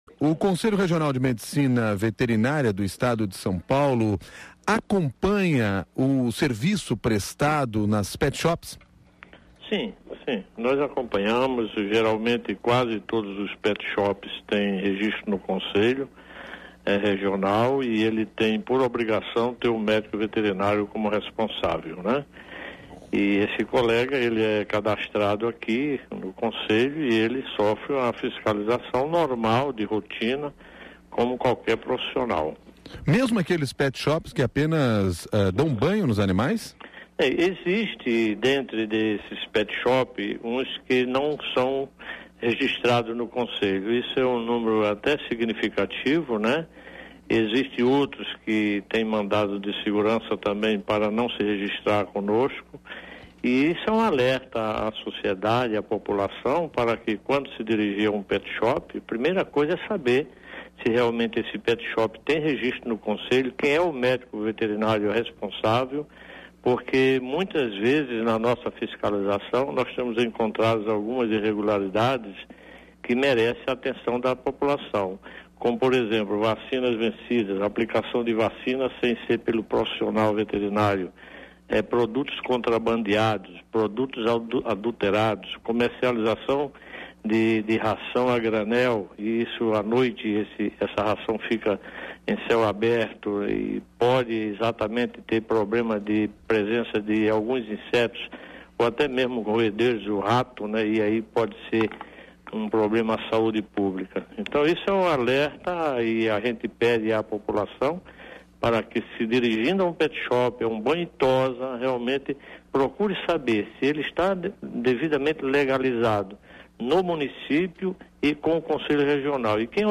Conselho Regional de Medicina Veterinária do Estado de São Paulo feitos no CBN São Paulo.